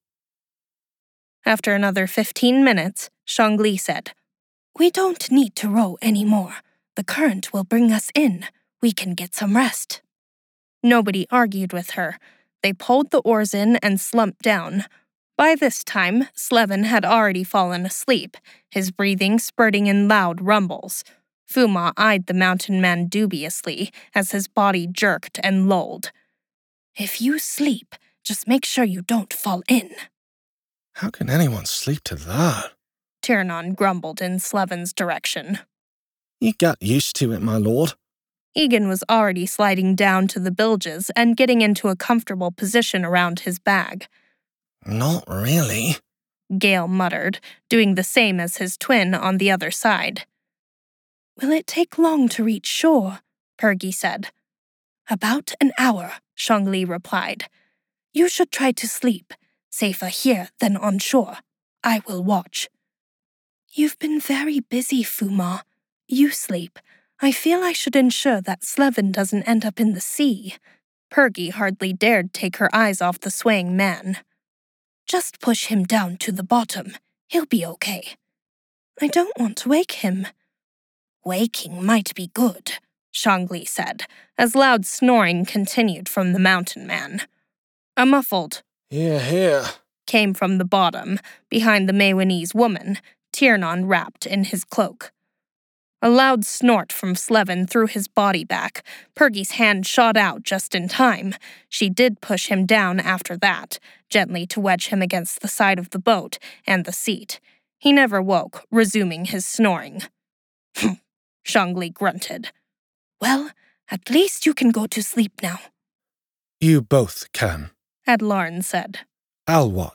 Rising on Song audiobook sample